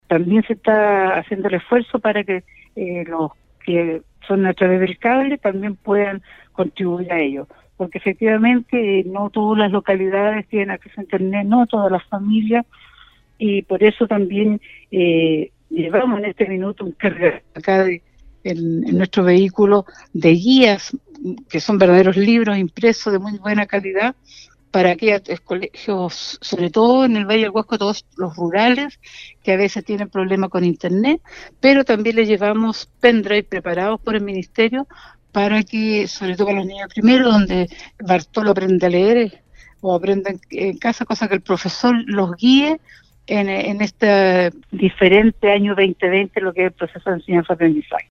La mañana de este martes, Silvia Álvarez, Seremi de educación de Atacama, sostuvo un contacto telefónico con el equipo de prensa de Nostálgica, donde se refirió a los desafíos de las clases virtuales, debido a la crisis sanitaria por la que se atraviesa en muchos países del mundo.